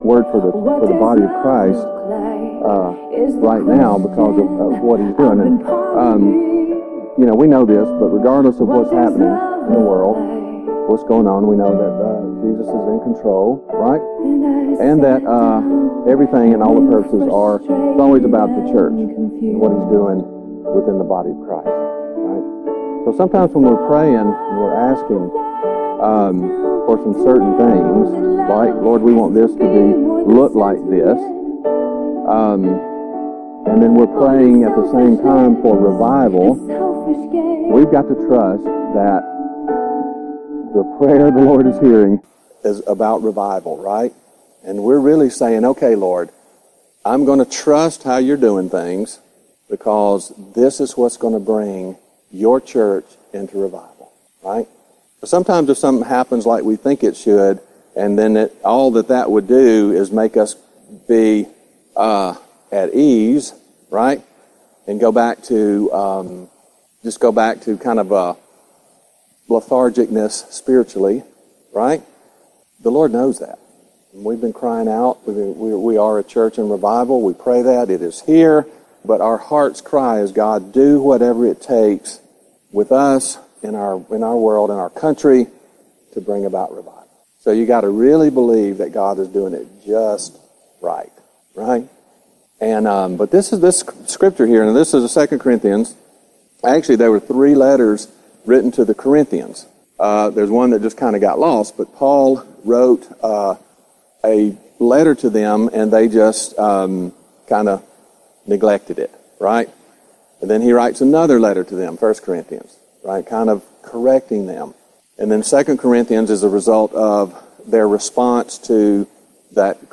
Service Type: CTK Noon Service